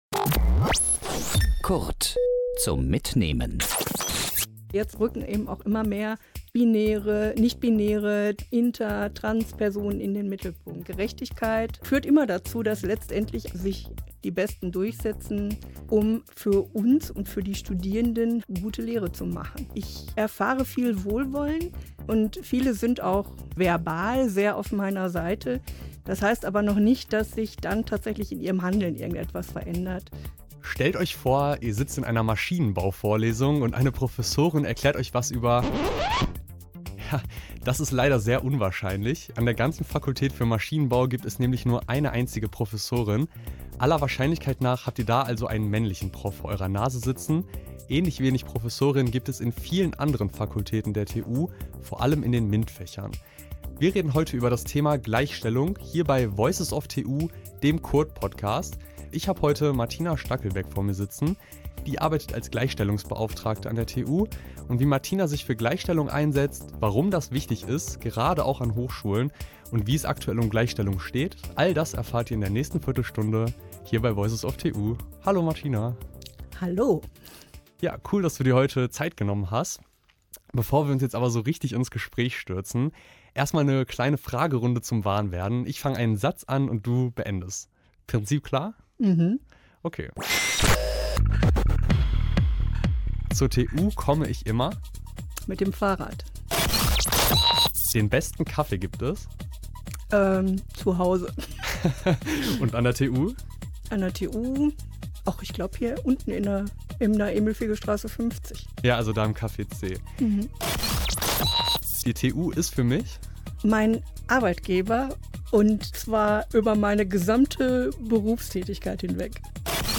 In der Vergangenheit bedeutete dies vor allem die Gleichberechtigung von Männern und Frauen. Mittlerweile stehen aber auch die verschiedenen Geschlechtsidentitäten im Fokus. Im Gespräch